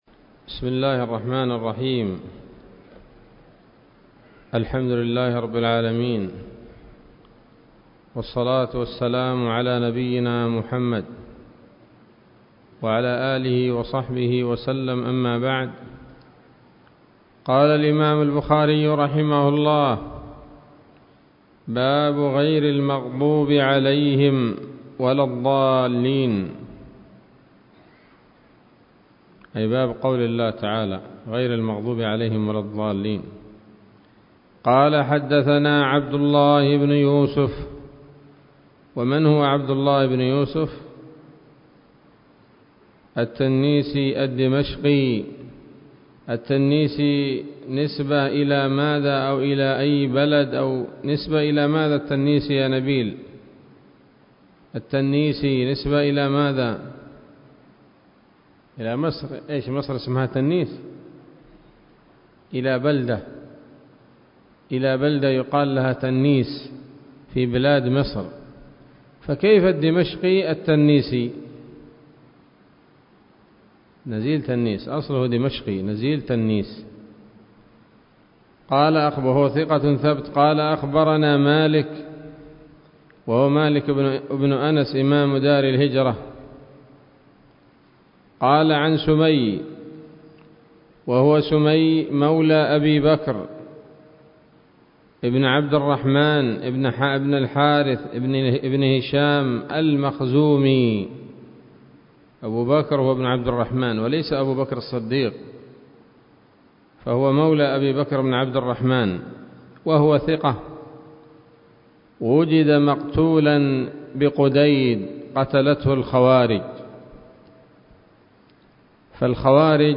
الدرس الثاني من كتاب التفسير من صحيح الإمام البخاري